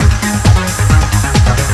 TECHNO125BPM 7.wav